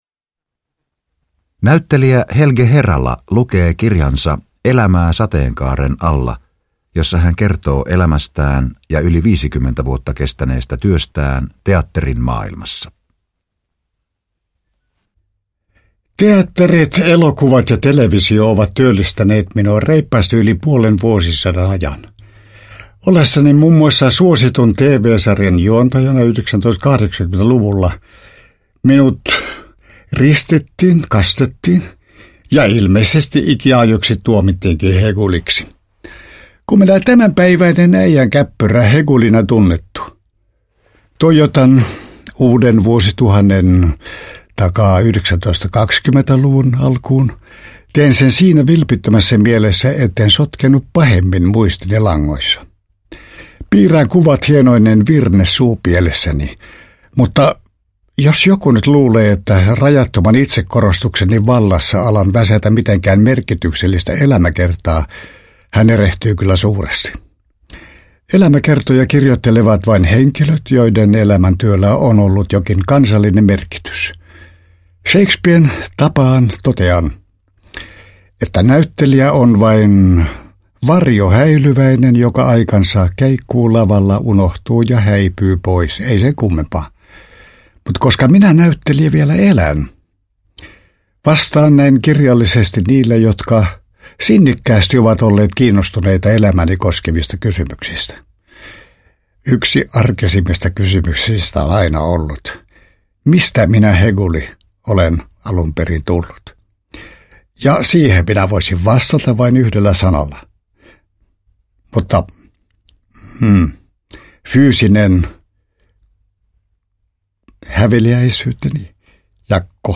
Suosittu taiteilija Helge Herala (1922-2010) kertoo omalla äänellään elämäntarinansa, jonka varrelle mahtuu verrattomia, velmulla huumorilla kerrottuja tapahtumia ja sattumuksia niin teatterin, elokuvan kuin television maailmasta - reippaasti yli puolen vuosisadan ajalta.
Uppläsare: Helge Herala